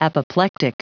Prononciation du mot apoplectic en anglais (fichier audio)
Prononciation du mot : apoplectic